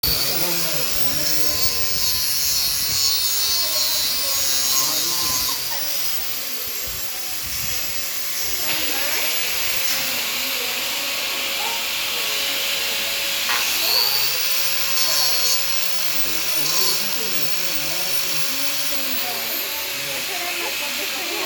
Grinder video 1.mp4